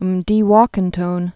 (əm-dē-wôkən-tōn, mĕdē-wô-)